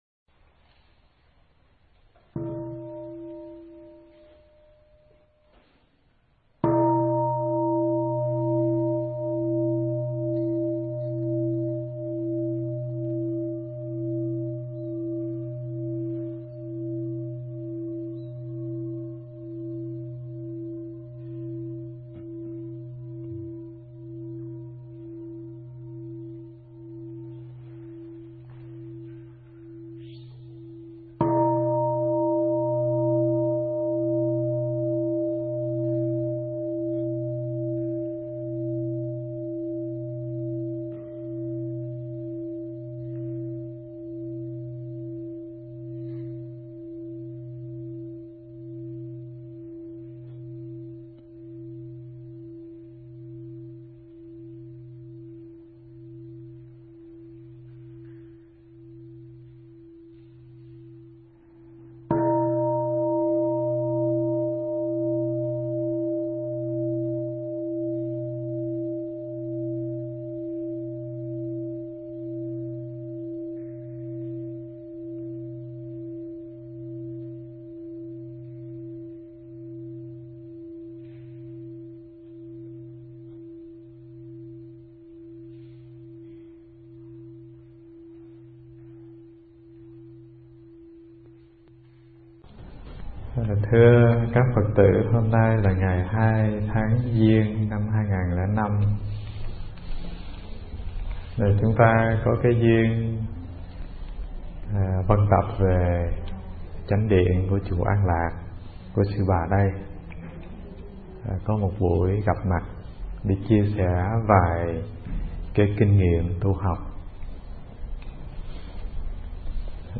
Mp3 thuyết pháp Đuổi Bắt Hạnh Phúc